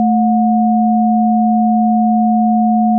Considérons un signal constitué de la somme de 2 composantes sinusoïdales
mix_amp_phase_2_freqs.mp3